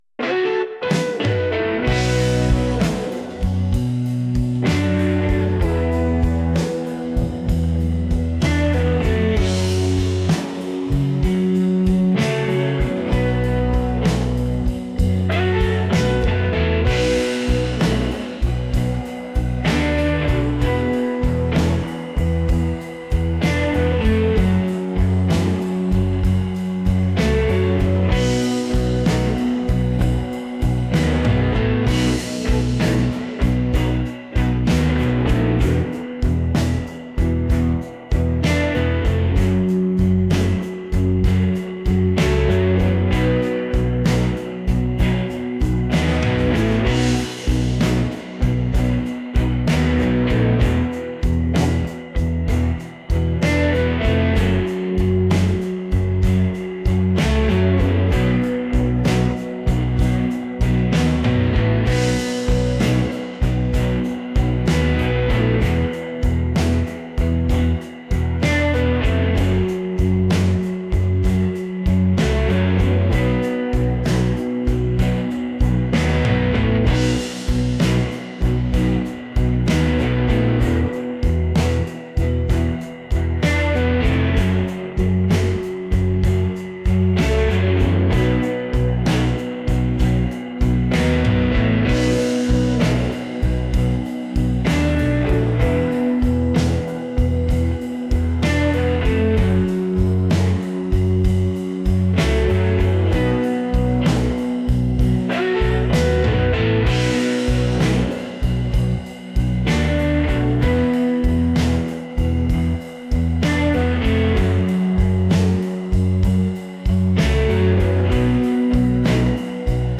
soulful | blues